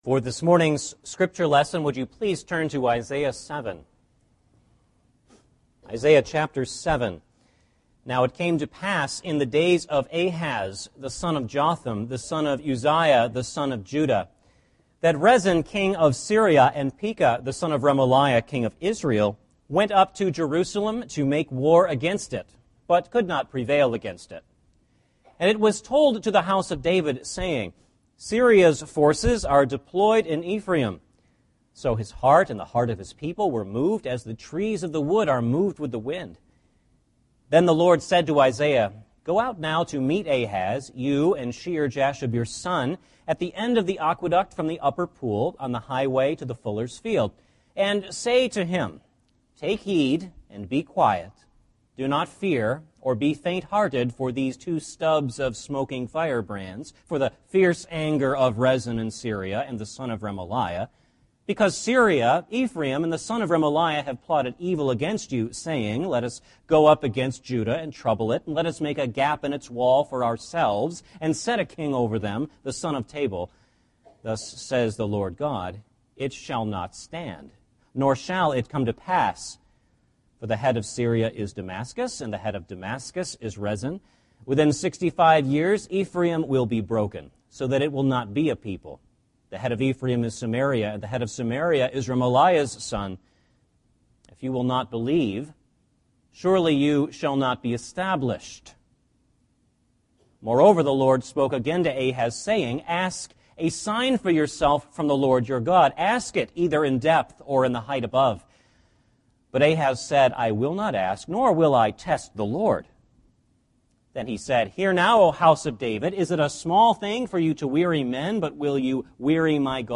Passage: Isaiah 7 Service Type: Sunday Morning Service